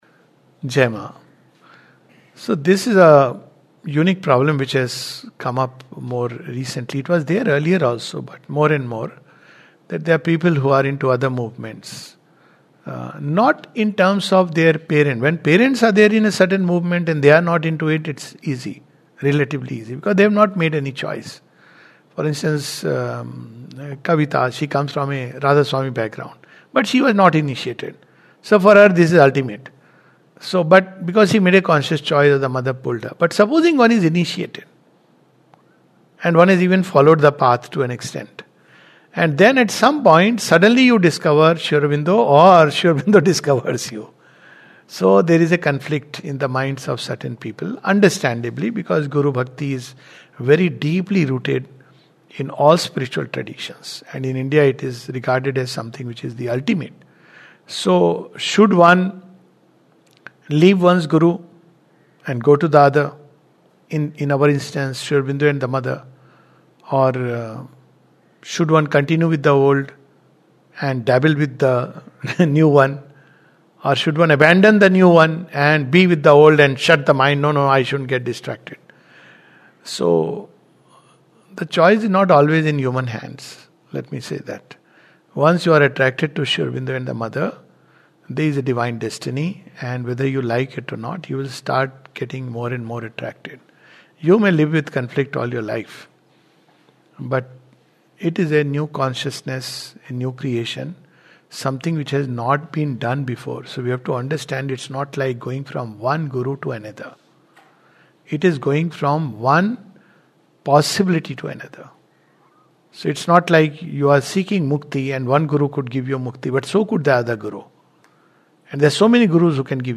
This reflection touches upon the conflict that some experience when they turn towards Sri Aurobindo's Yoga. A talk